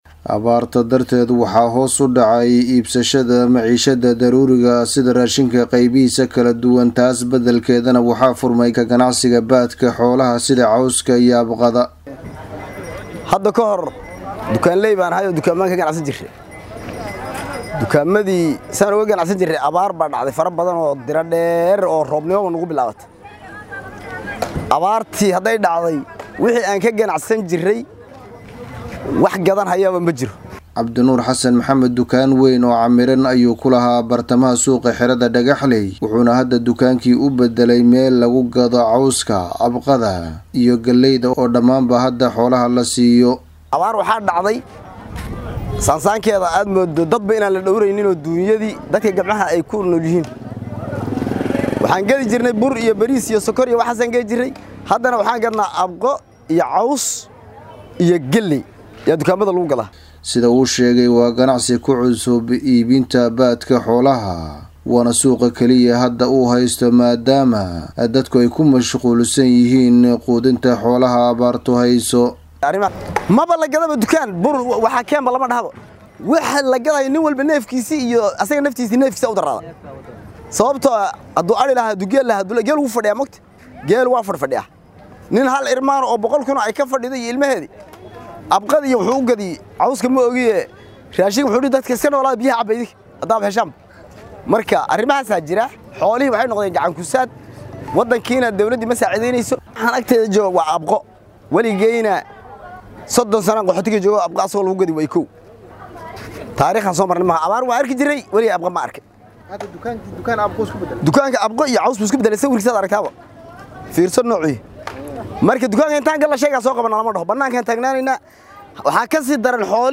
Wararka Kenya